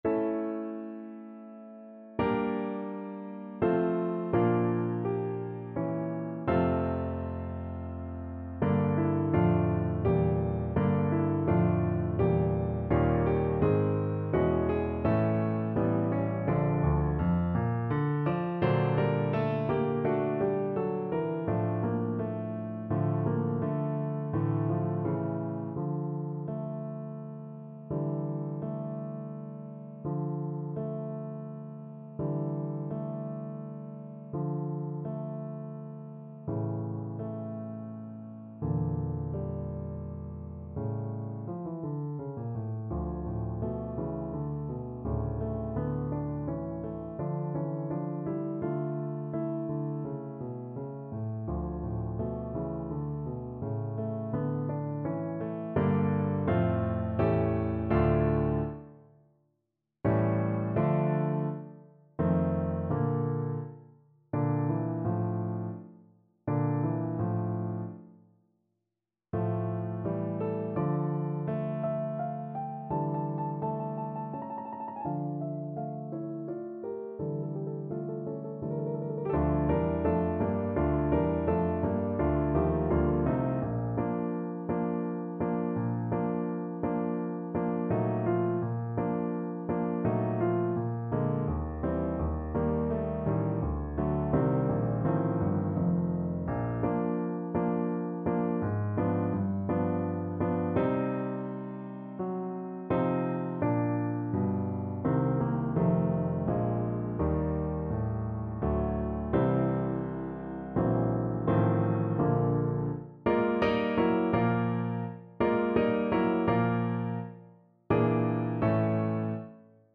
3/4 (View more 3/4 Music)
Andante =84
Classical (View more Classical Cello Music)